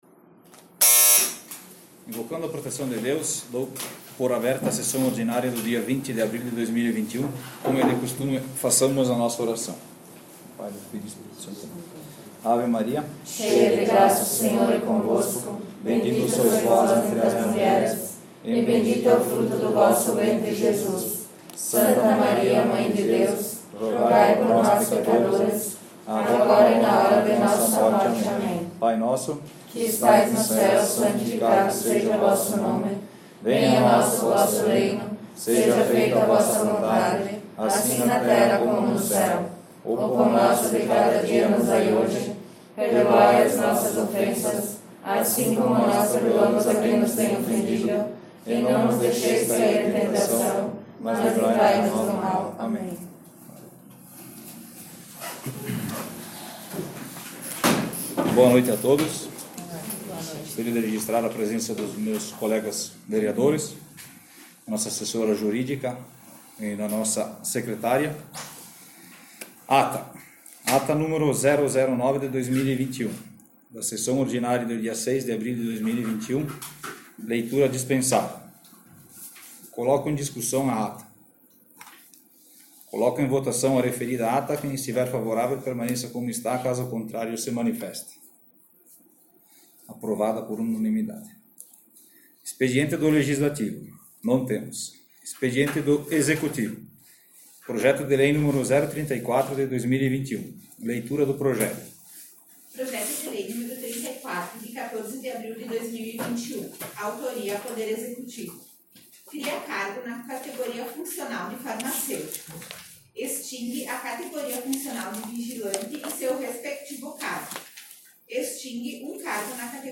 Sessão Ordinária 20/04/2021